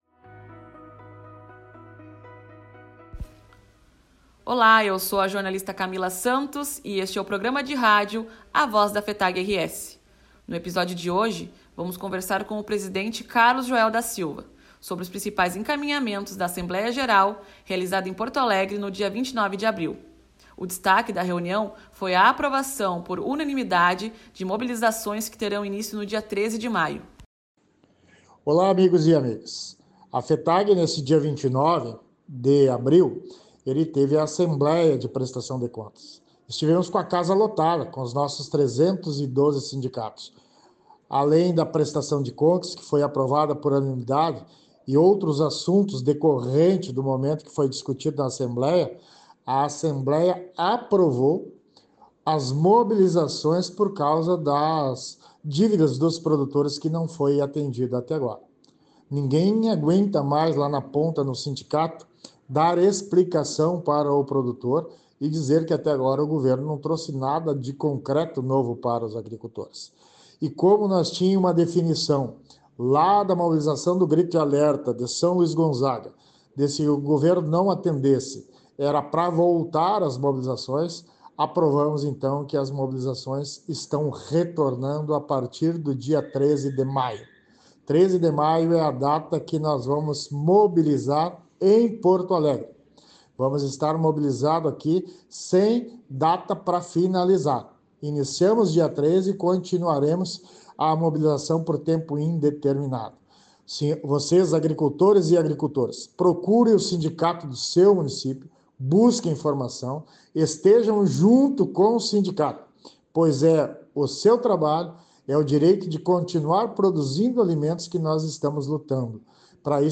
A cada programa, dois jovens agricultores e dirigentes da juventude sindical vão compartilhar suas histórias, seus sonhos e o que os inspira a seguir firmes na luta por um campo vivo e com futuro.